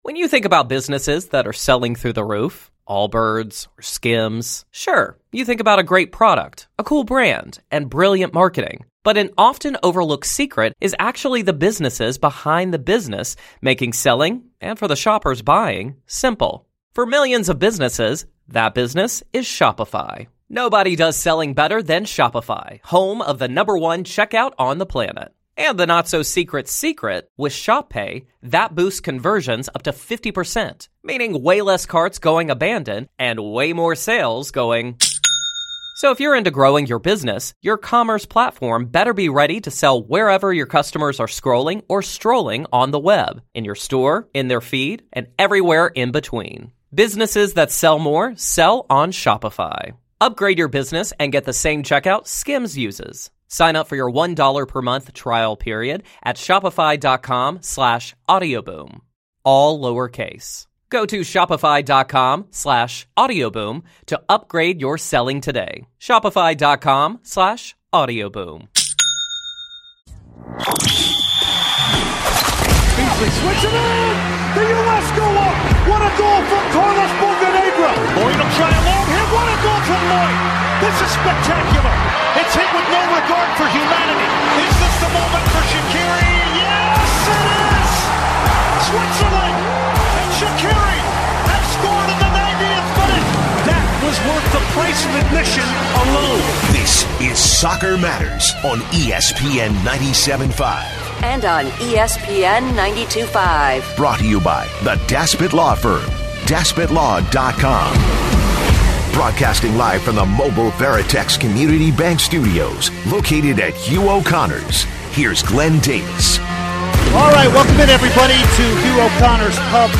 holiday party, LIVE from Hugh O'Connors Pub!
Hour 1 featured conversations with...